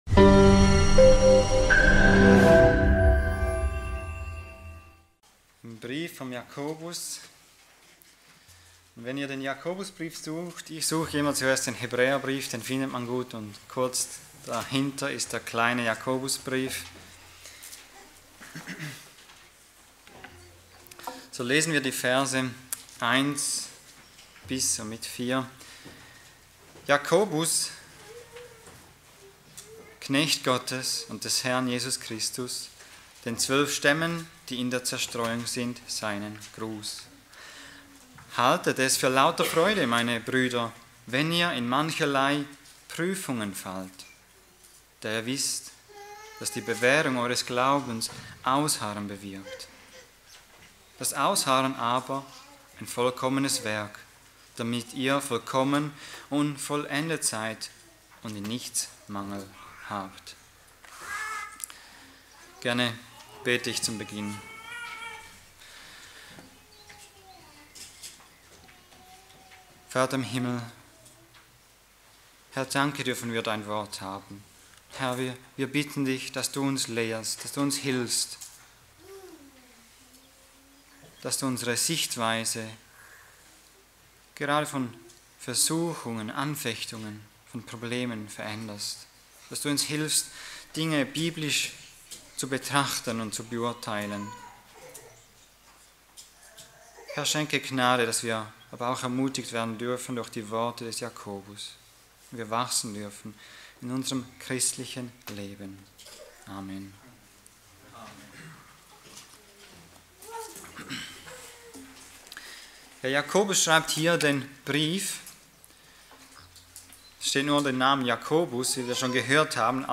Eine predigt aus der serie "Jakobus."